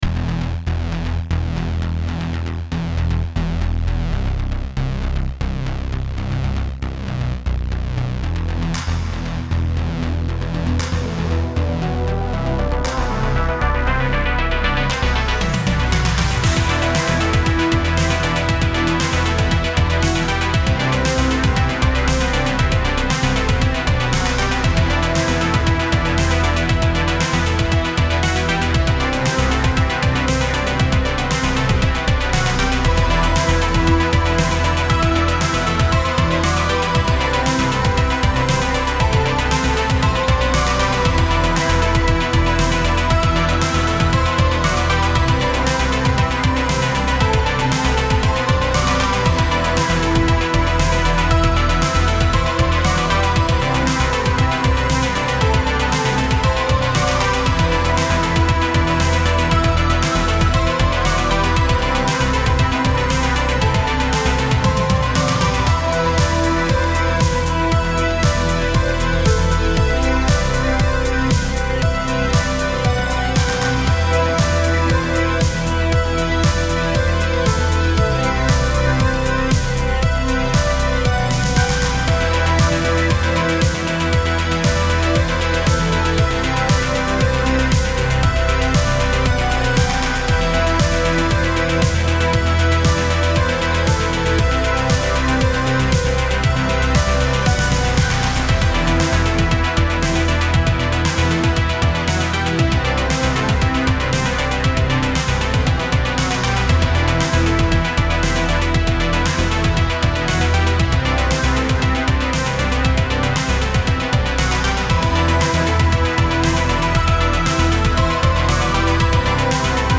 Genre: spacesynth.